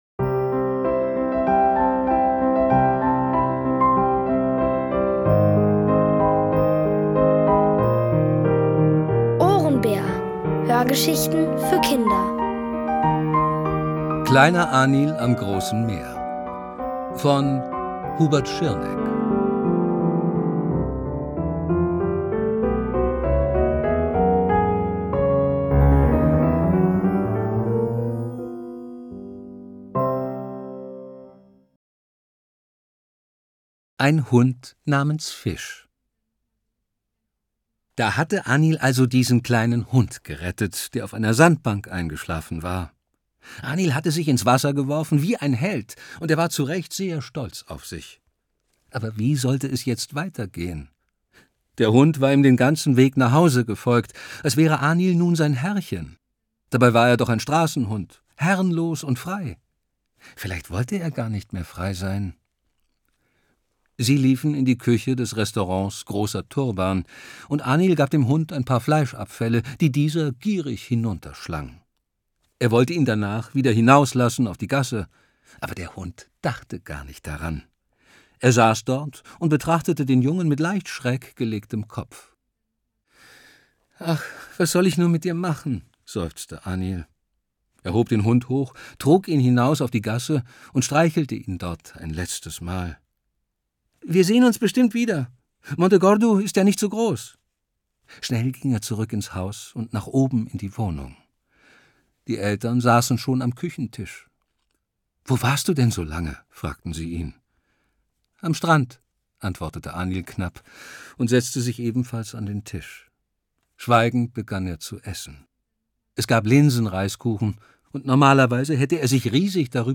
Aus der OHRENBÄR-Hörgeschichte: Kleiner Anil am großen Meer (Folge 4 von 7) von Hubert Schirneck.